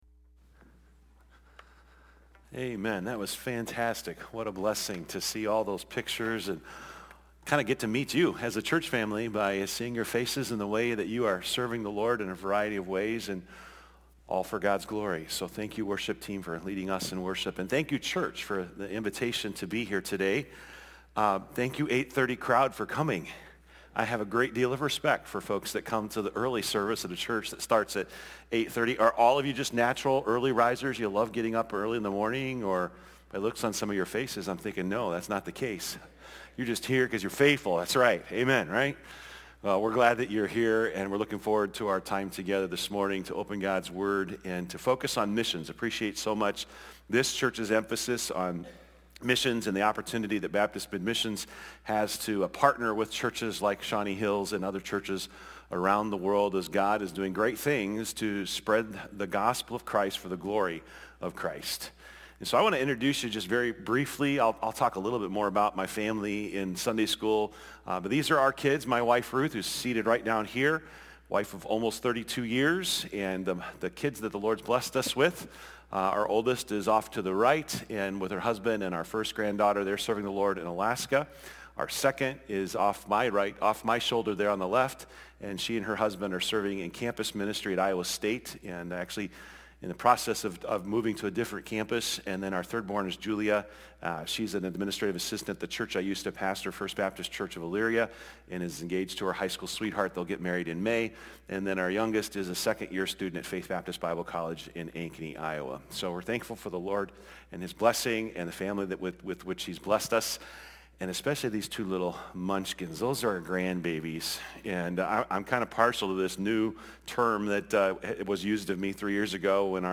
Guest Speaker « Fear Not!